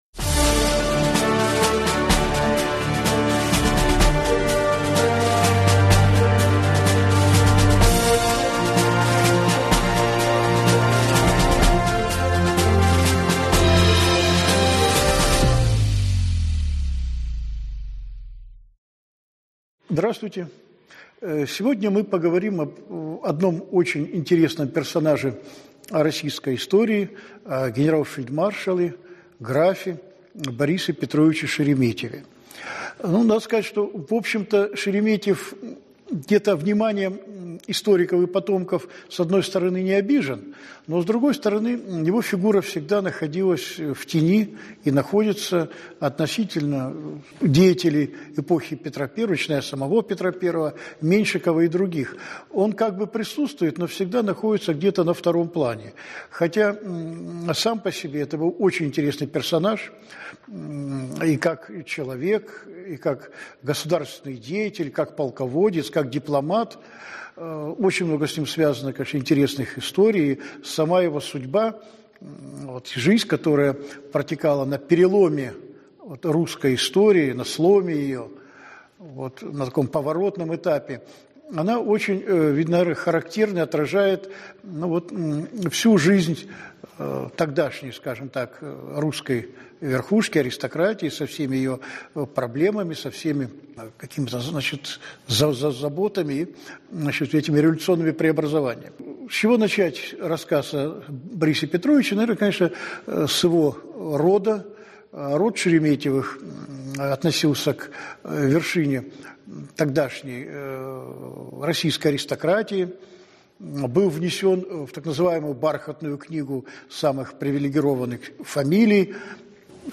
Аудиокнига Фельдмаршал Шереметев. Подвиги и трагедии | Библиотека аудиокниг